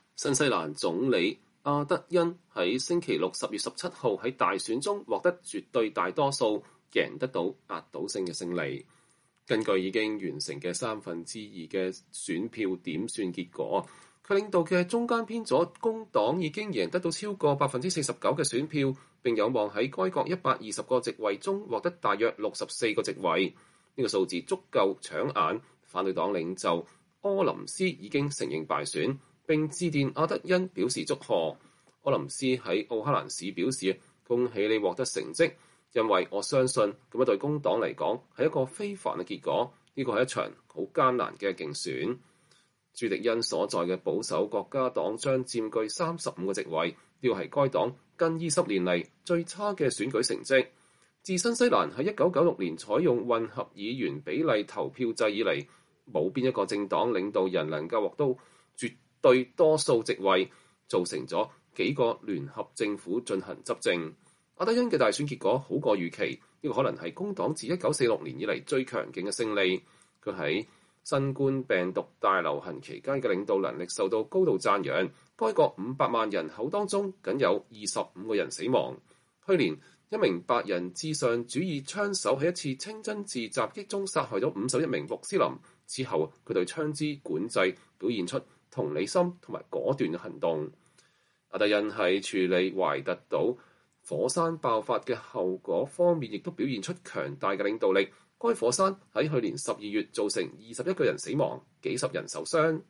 新西蘭總理傑辛達·阿德恩(Jacinda Ardern)在工黨獲勝後的聚會上發表講話。